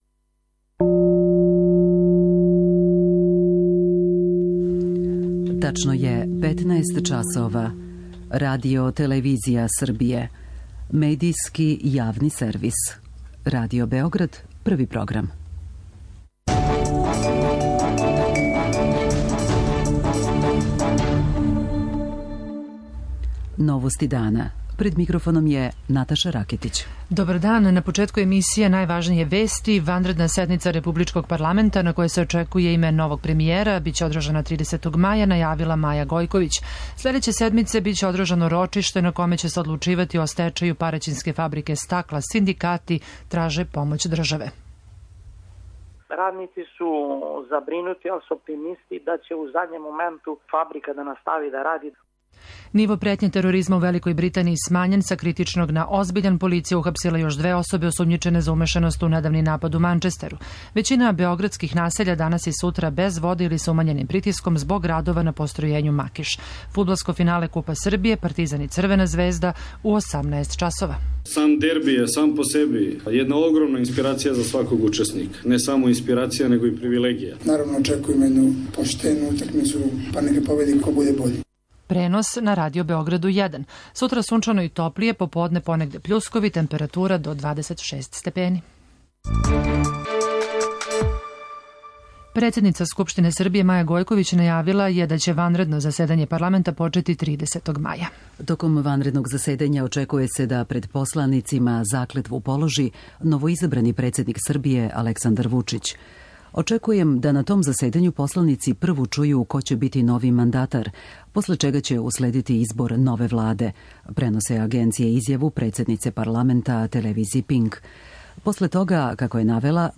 Још од тада, представља релевантан и свеобухватан преглед најважнијих информација и догађаја из земље и света.